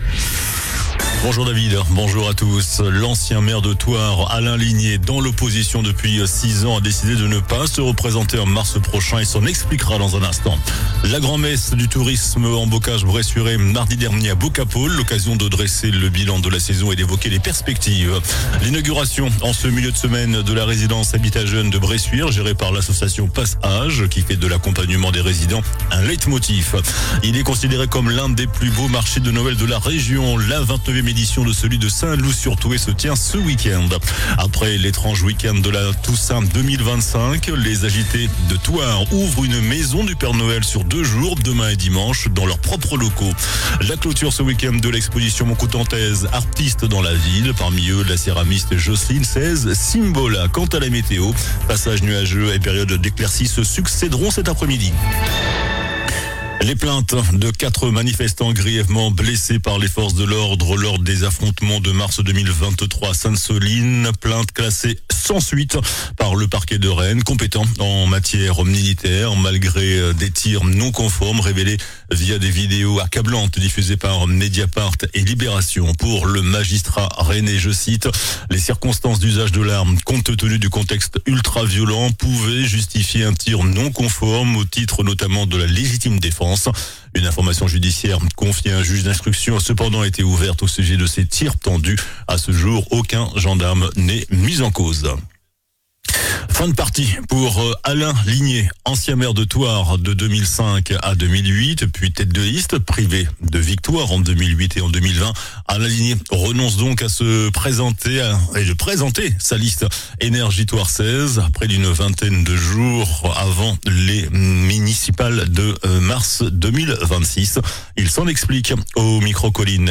JOURNAL DU VENDREDI 05 DECEMBRE ( MIDI )